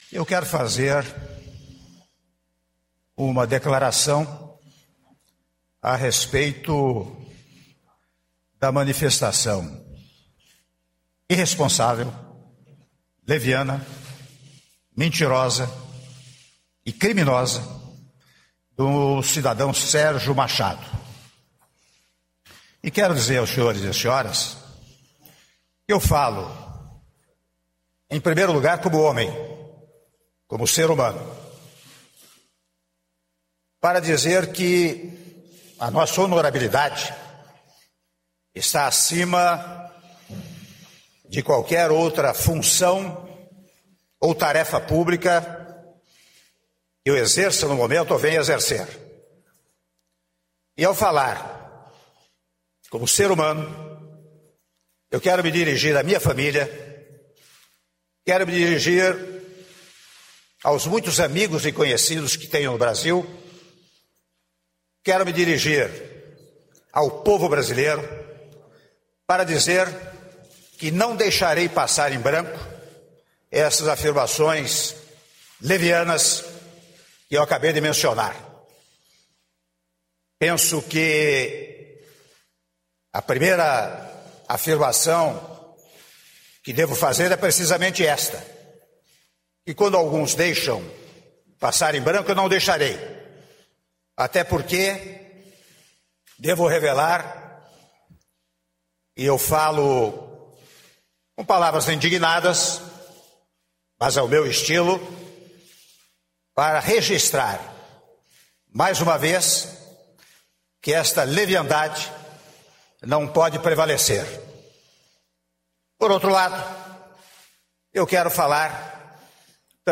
Ouça trecho do discurso de Temer em resposta a Sérgio Machado